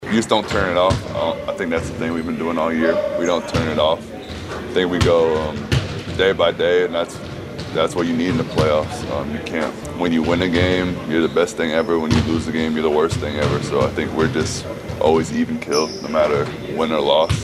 Thunder center Isiah Hartenstein talks about how OKC can stay focused into game two.